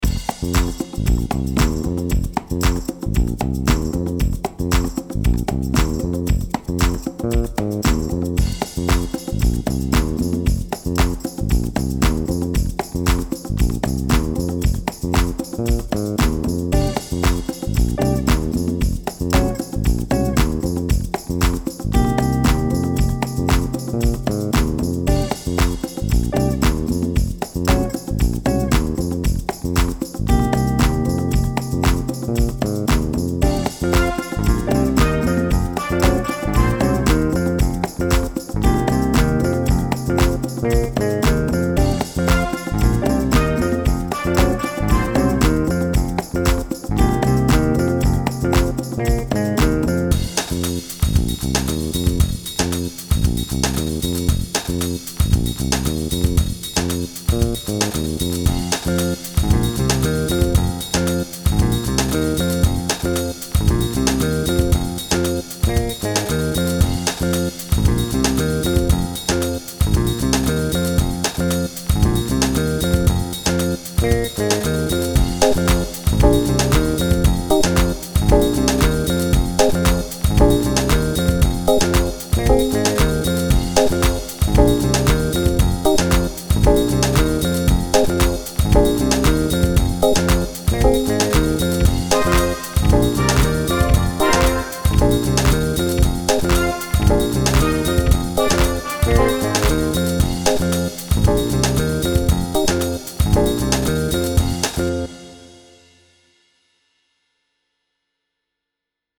Home > Music > Blues > Bright > Smooth > Laid Back